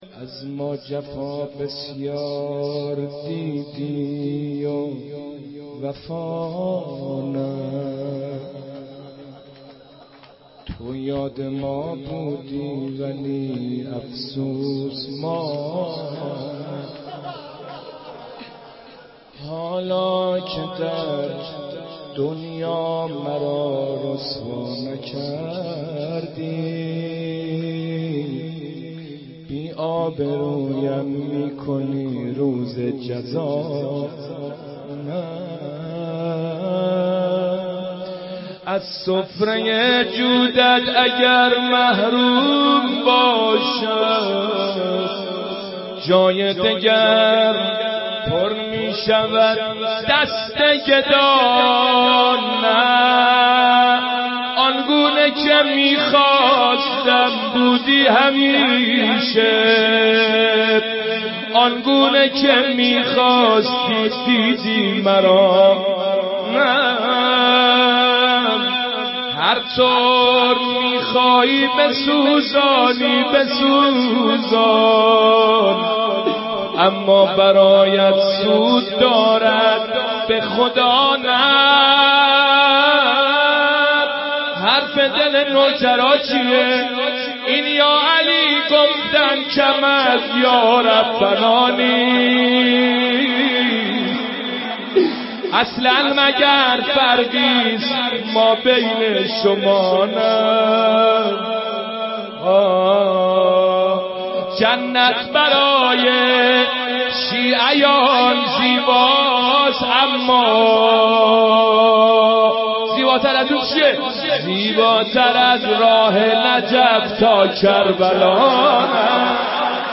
roze-nohekhan-1.mp3